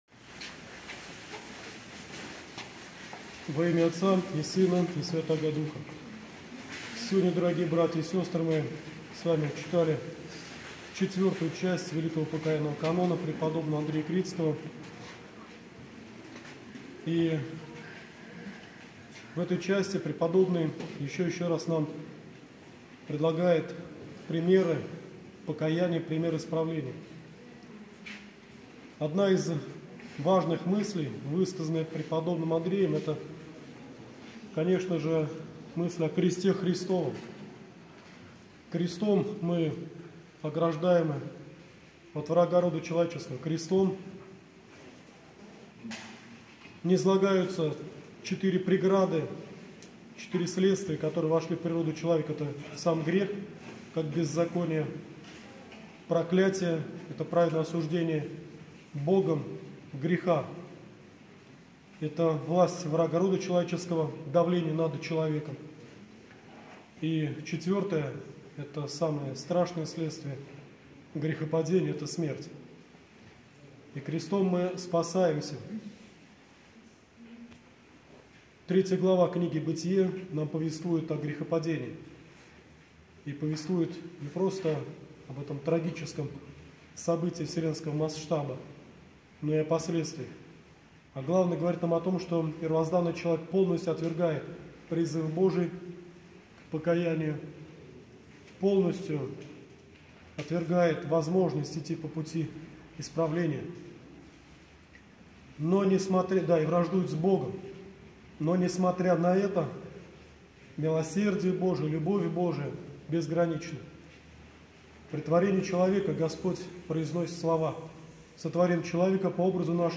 Проповедь для прихожан